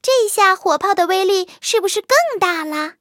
卡尔臼炮强化语音.OGG